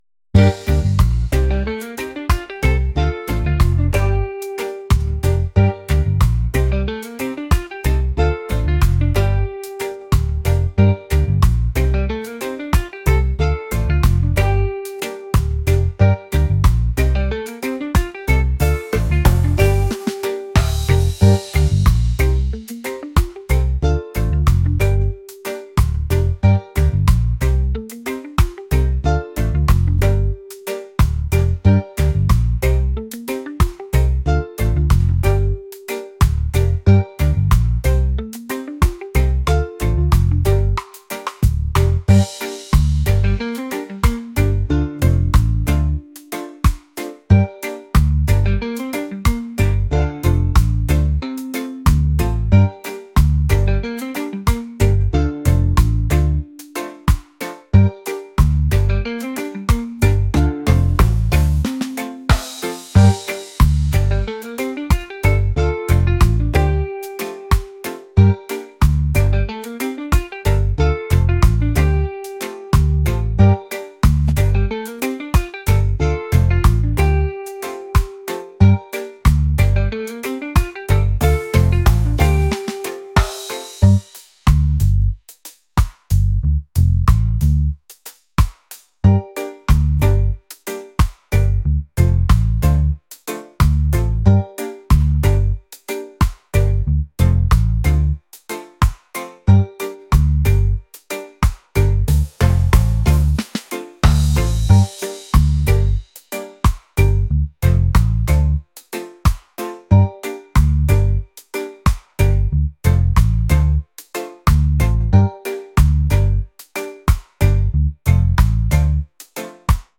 reggae | soul & rnb | lounge